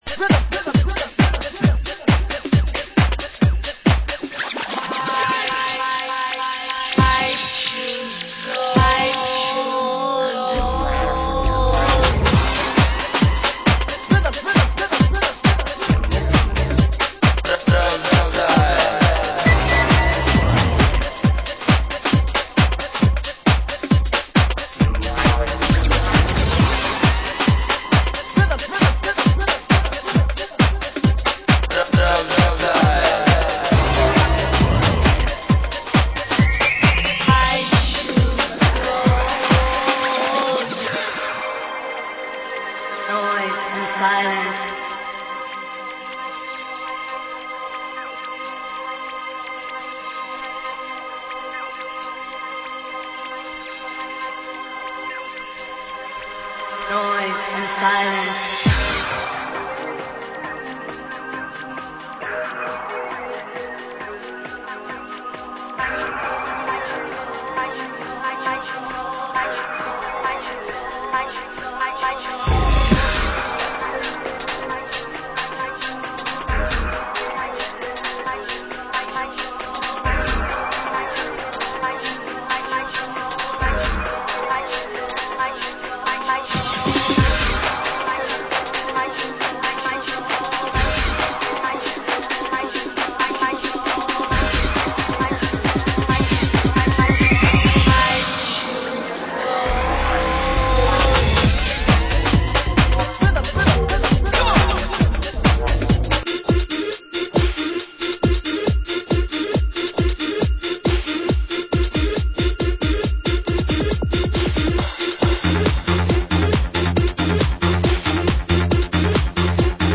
Format: Vinyl 12 Inch
Genre: UK Garage